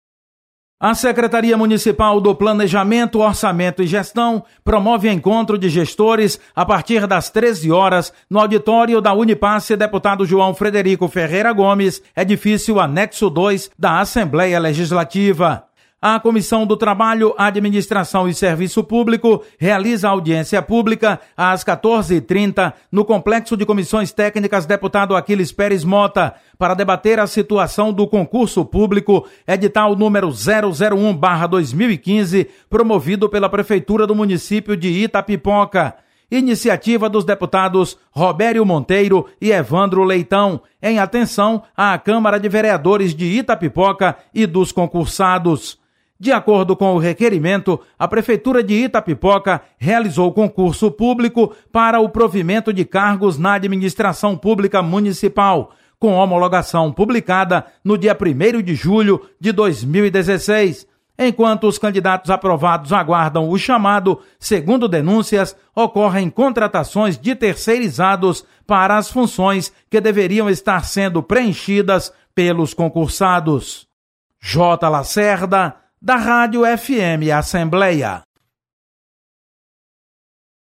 Você está aqui: Início Comunicação Rádio FM Assembleia Notícias Agenda